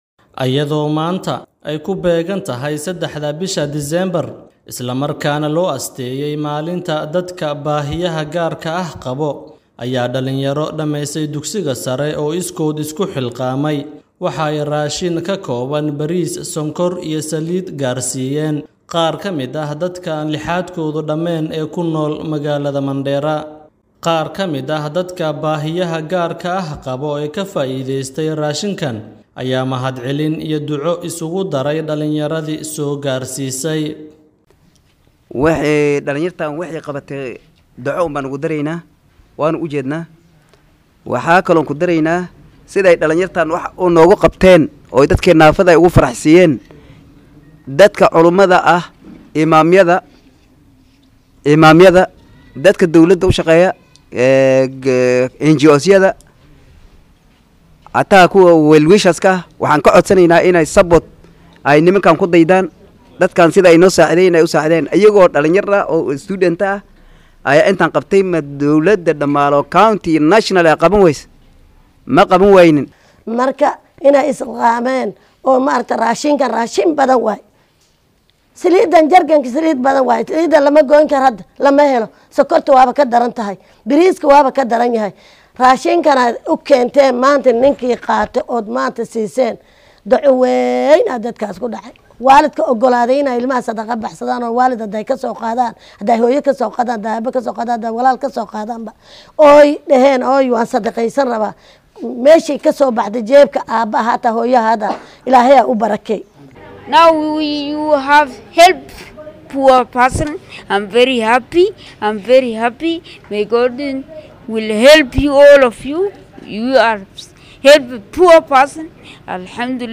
Arday dhameeyay dugsiyada sare ayaa deeq raashiin ah gaarsiiyay qaar ka mid ah dadka qabo baahiyaha gaarka ah ee ku nool Mandera sida uu ku soo warramaya